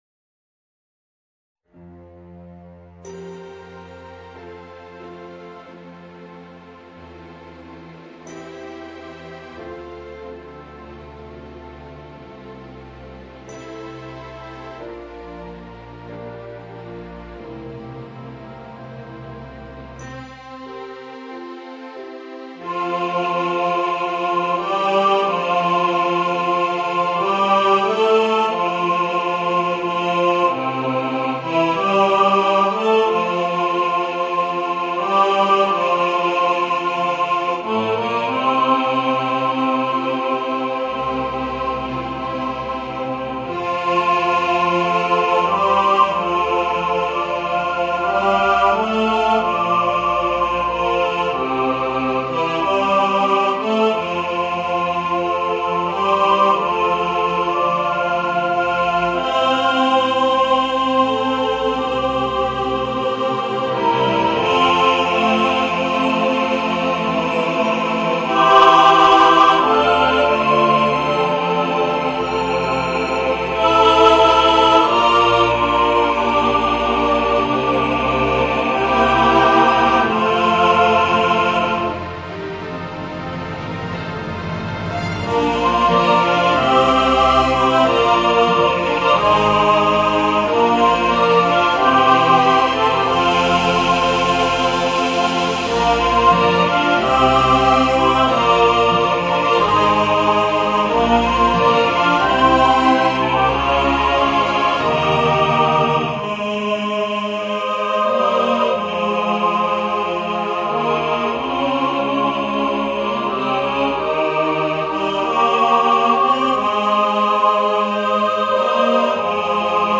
Voicing: Chorus and Orchestra